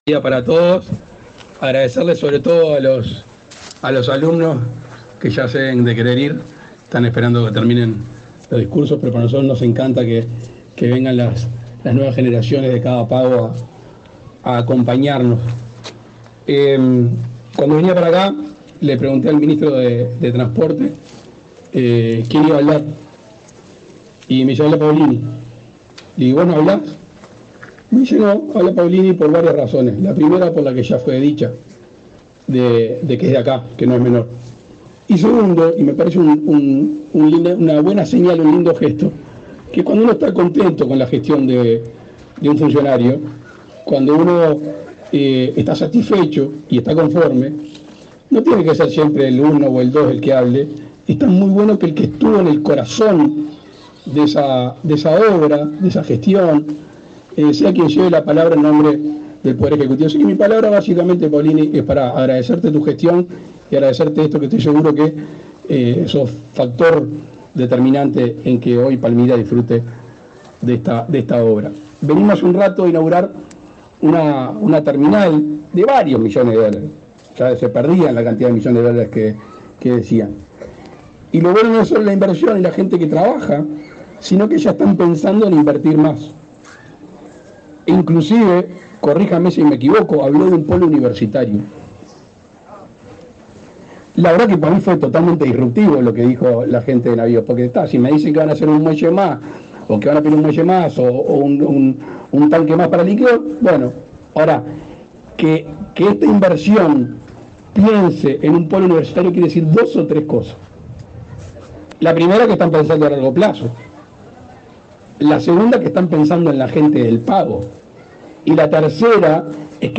Palabras del presidente Luis Lacalle Pou
El presidente Luis Lacalle Pou encabezó, este viernes 12 en Colonia, el acto de inauguración de la terminal fluvial internacional de pasajeros del